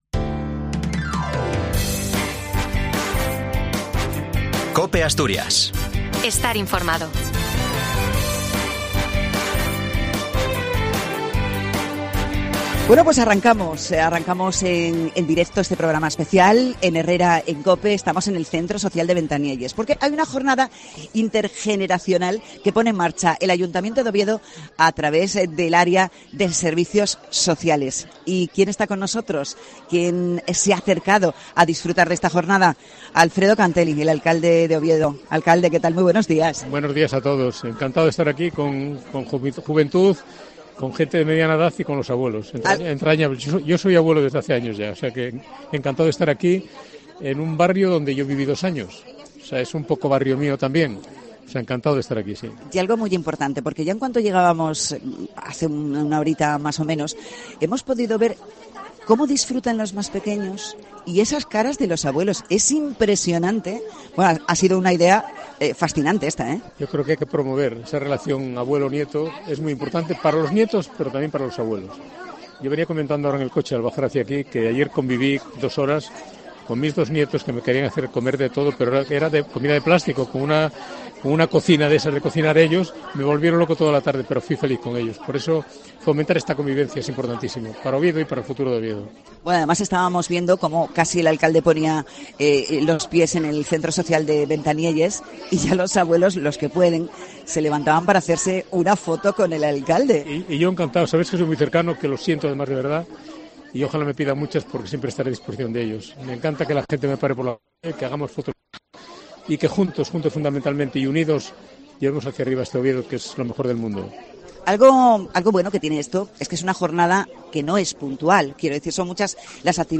Programa desde el Centro Social de Ventanielles